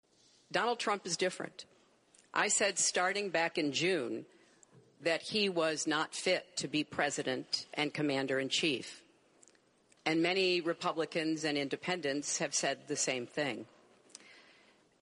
Este segundo debate presidencial decorreu na Washington University in St. Louis, no estado do Missouri, e teve um formato bastante diferente do primeiro frente-a-frente entre os dois candidatos durante o qual responderam a perguntas de um único moderador.